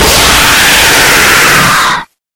Звуки прыжков
zvuki_prizhkov_kyy.mp3